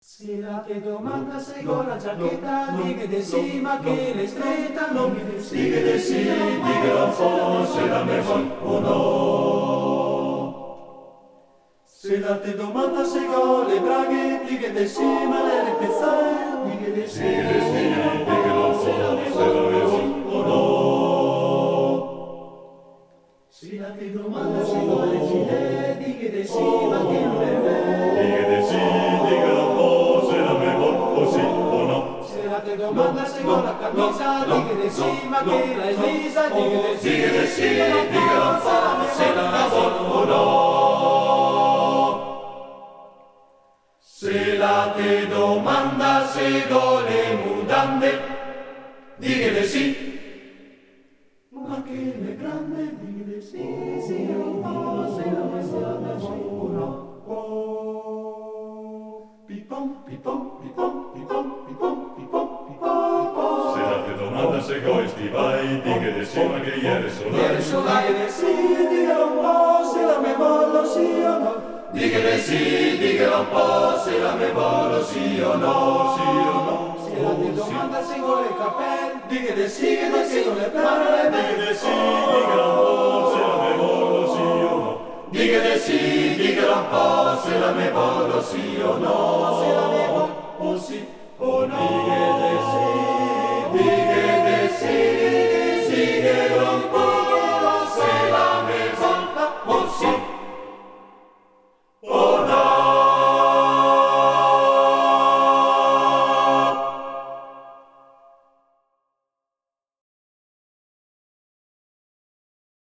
Arrangiatore: Franceschini, Armando
Esecutore: Coro Genzianella